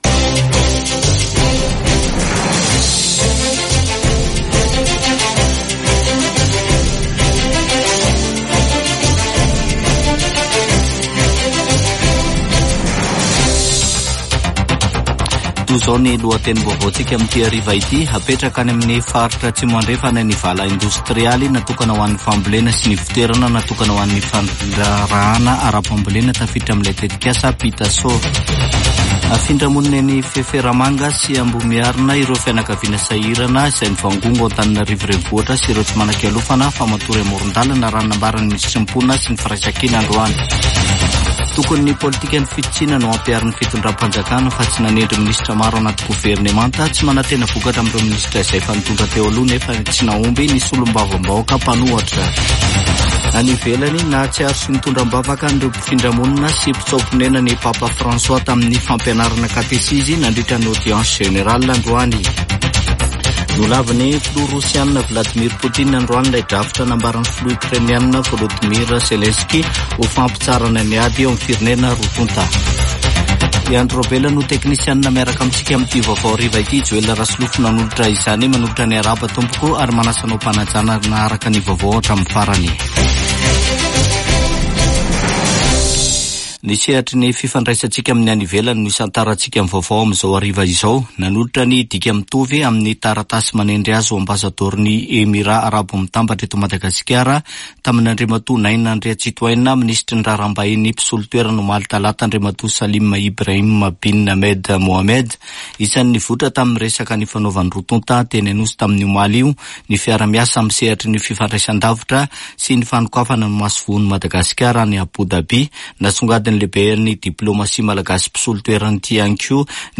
[Vaovao hariva] Alarobia 28 aogositra 2024